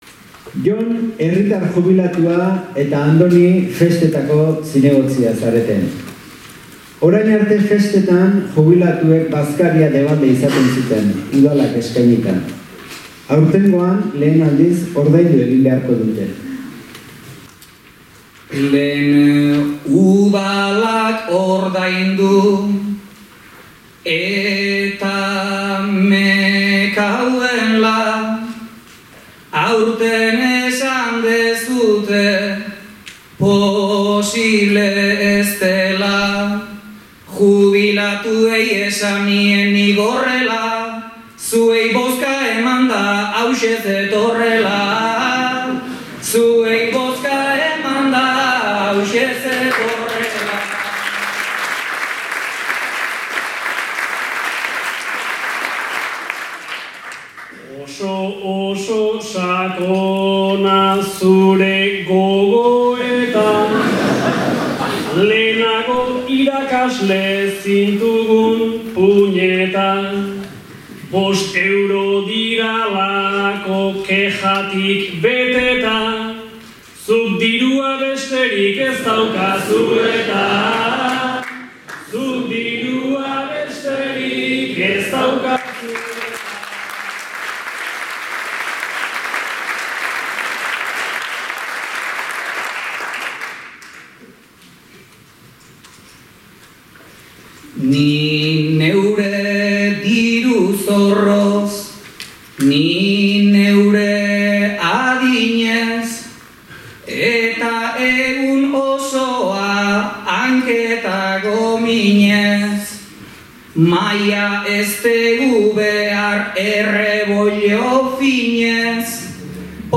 Gipuzkoako Bertsolari Txapelketa. 1. Final zortzirena